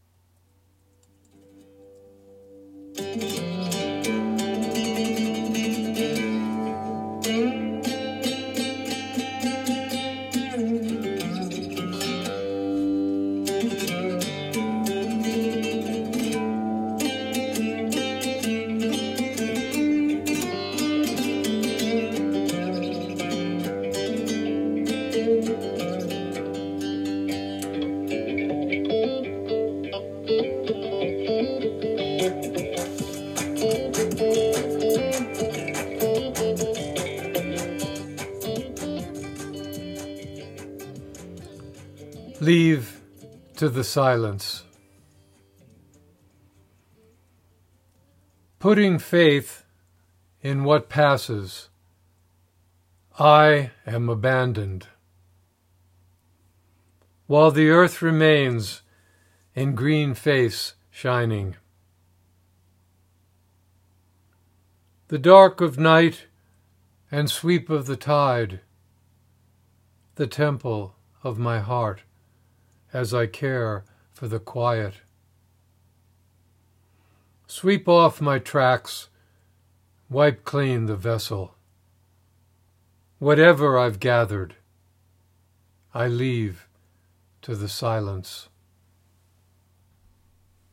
Reading of “Leave to the Silence” with music by Tinariwen